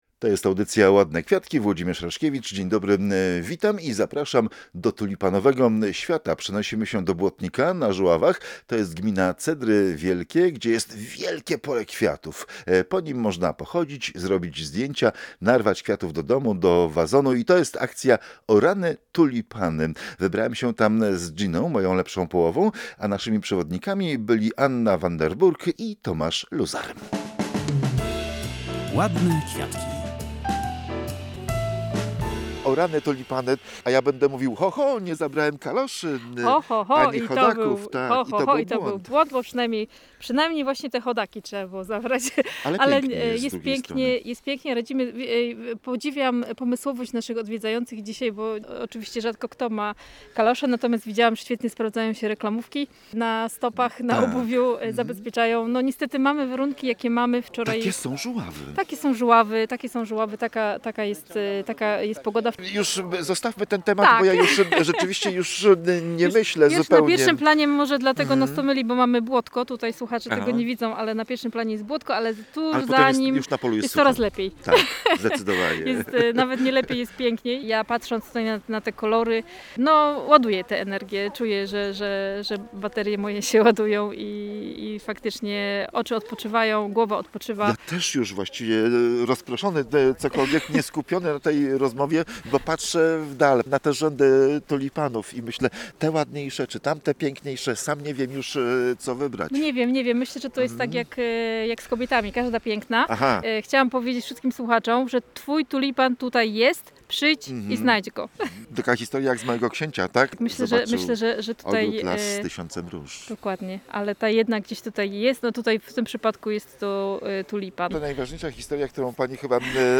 W audycji odwiedzamy Błotnik na Żuławach. Tam można wybrać się na ogromne pole tulipanów i poczuć jak w Holandii.